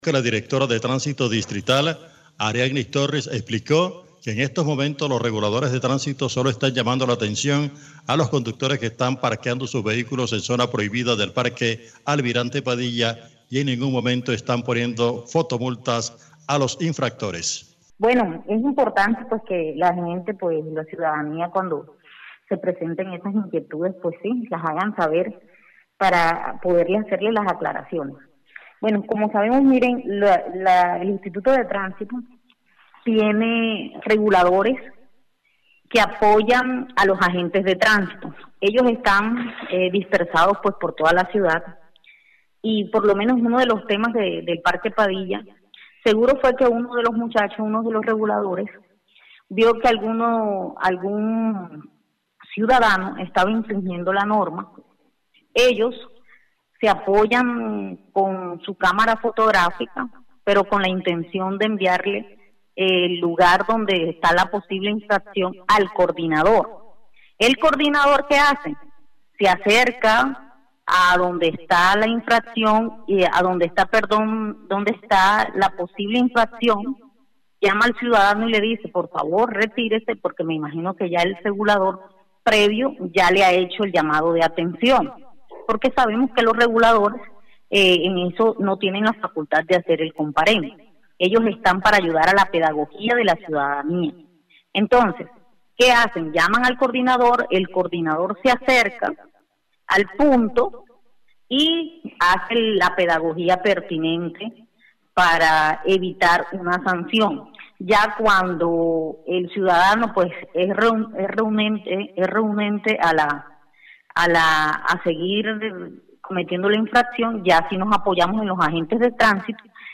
La directora de Tránsito de Riohacha, Ariannis Torres, aclaró en Cardenal Stereo que los reguladores de tránsito en la ciudad no están haciendo fotomultas.